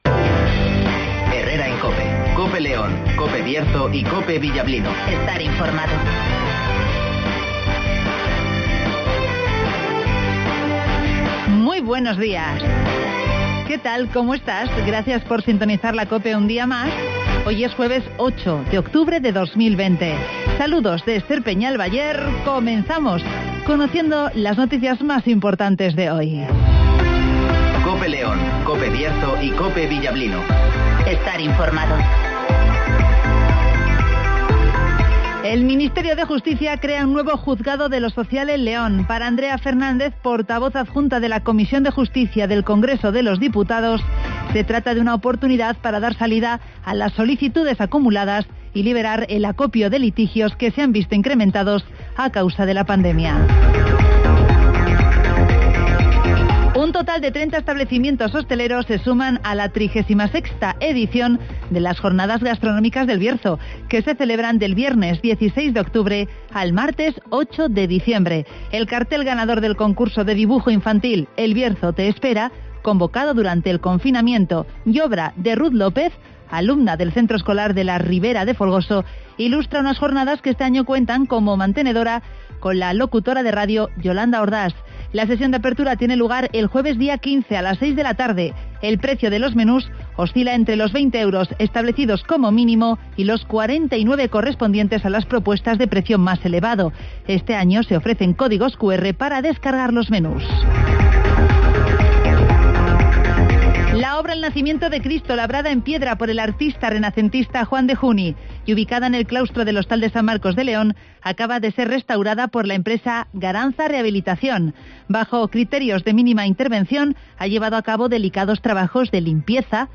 -Avance informativo